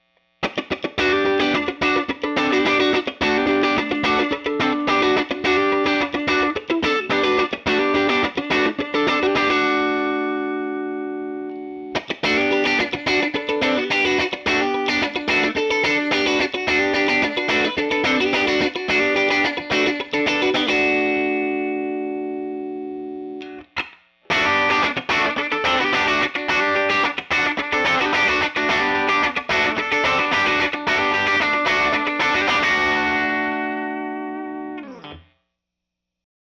1953 Tele Set Vox AC30 / Celestion AlNiCo Blue Speakers - Neck Middle Bridge